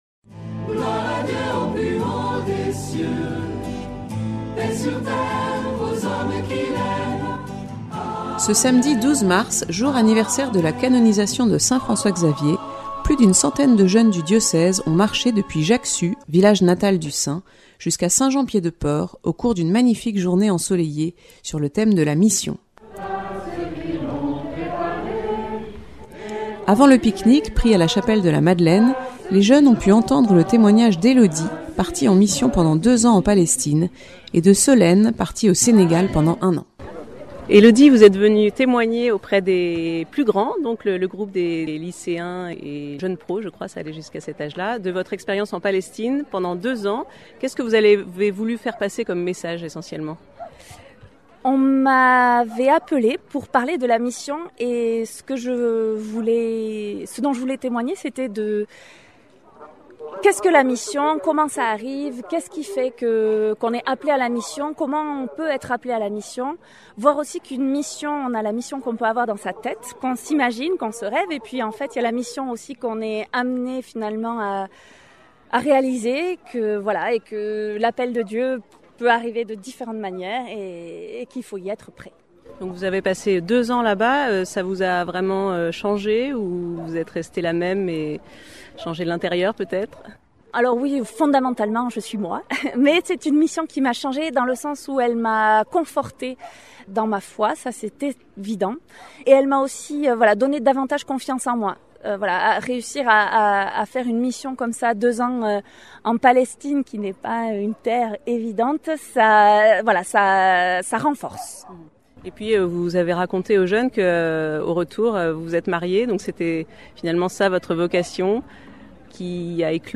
Voir le reportage et les photos.